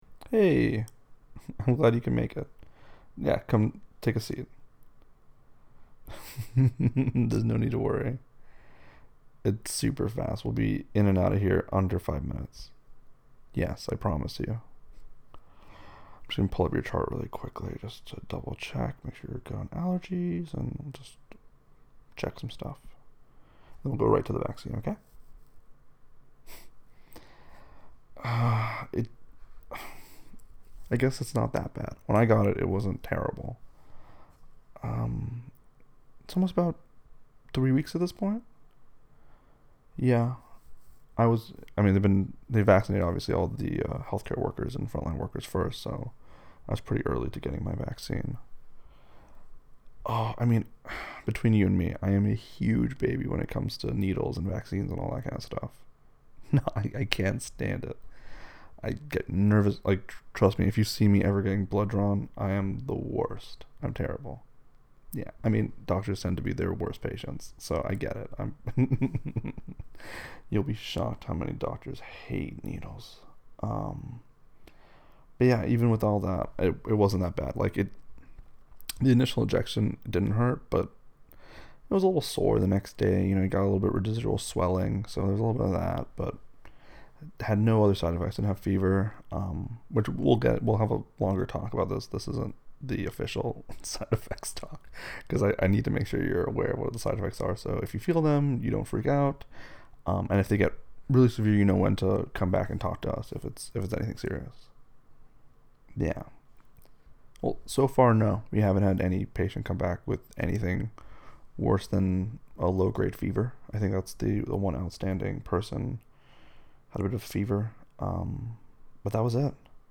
The following audio is meant solely as a role-play and for entertainment purpose only.